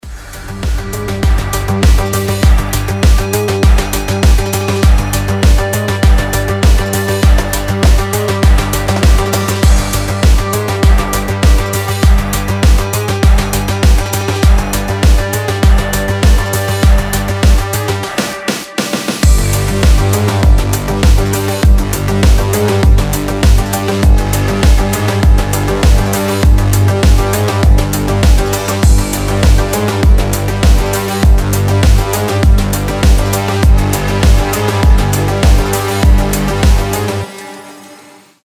• Качество: 320, Stereo
без слов
Midtempo
динамичные